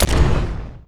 explode.wav